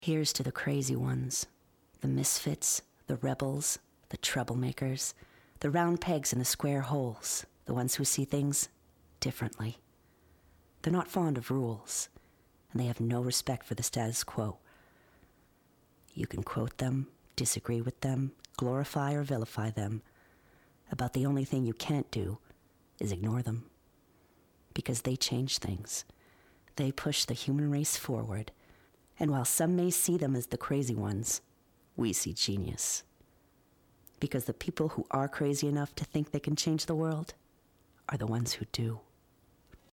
Narration 2 - ANG